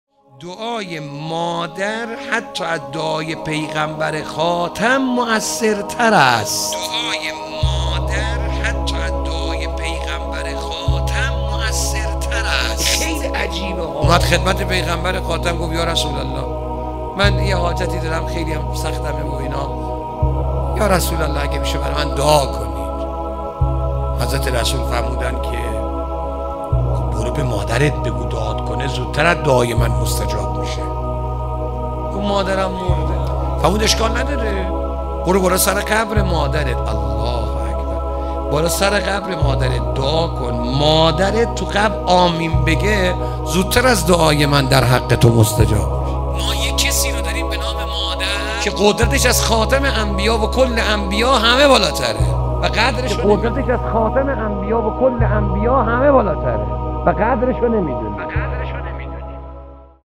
ولادت حضرت زینب (س) | موکب لواءالزینب (س) طهران